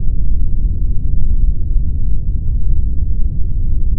rumble.wav